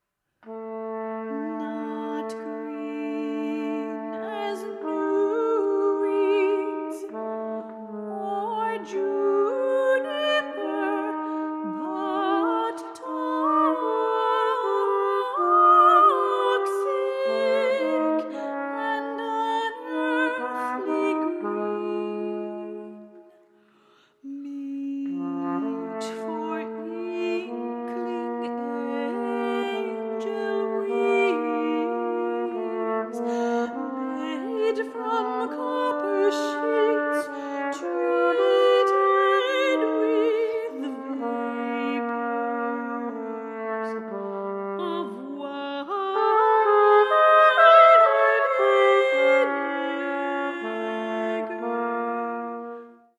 trumpet and soprano ensemble